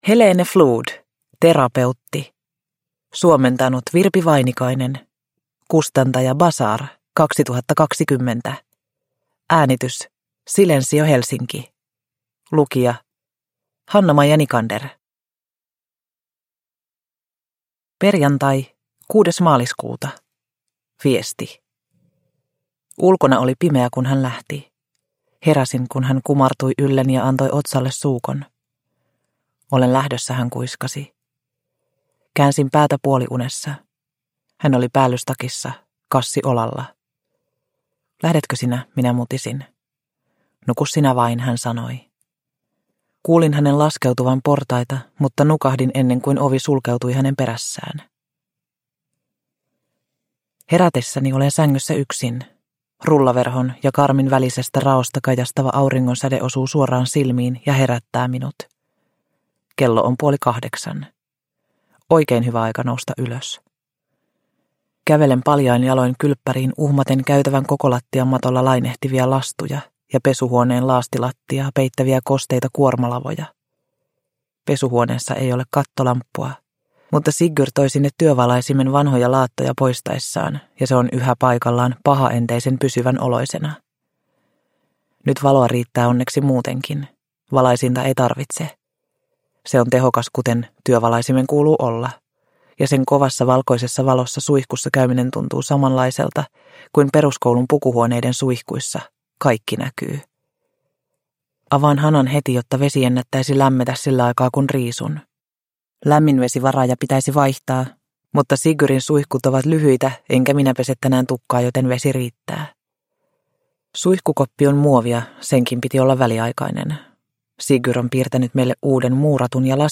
Terapeutti – Ljudbok – Laddas ner